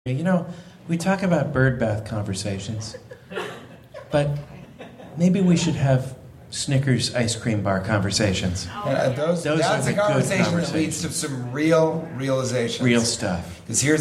from Tangled Twins- Live from San... at 1:08:40
A conversation that leads to some real realizations.